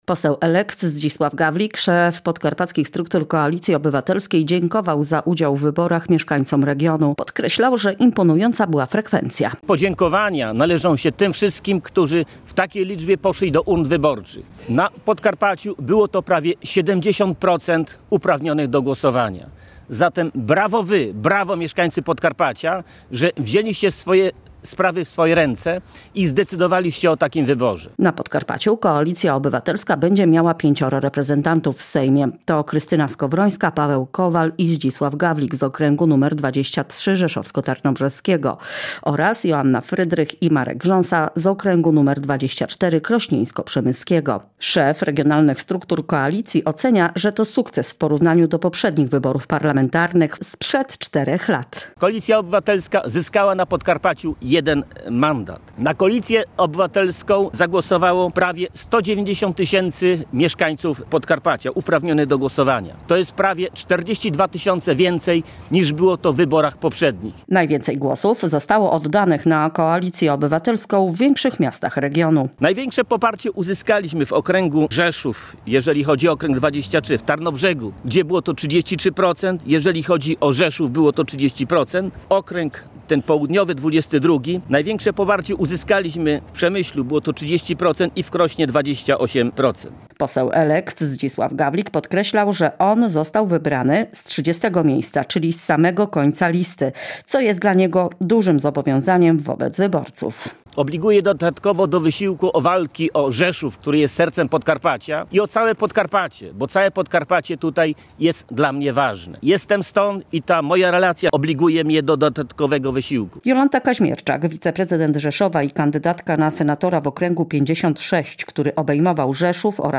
Dziś na Rynku w Rzeszowie to ugrupowanie podsumowało kampanię do Parlamentu.